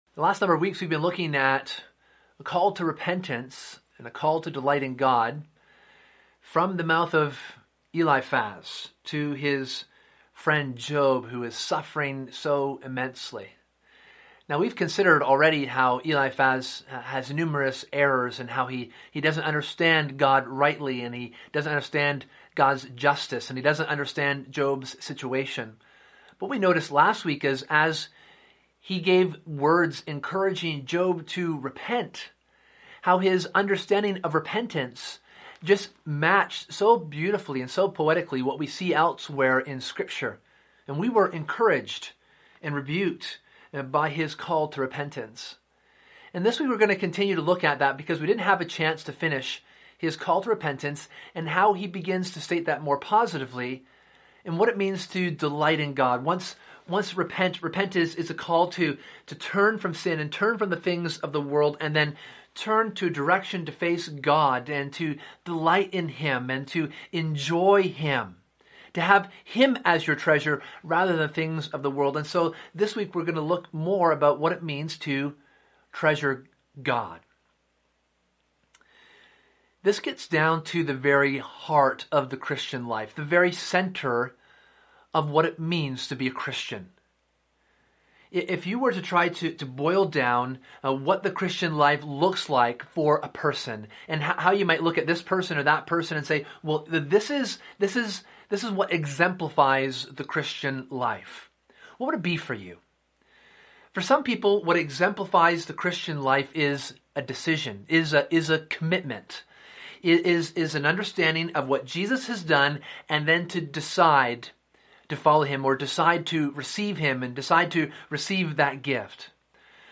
Sunday AM